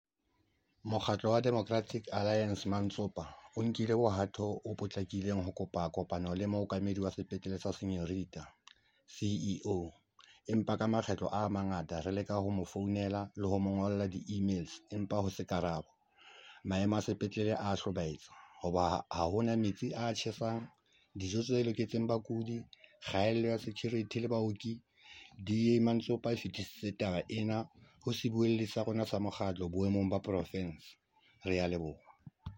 Sesotho soundbites by Cllr Leonard Masilo and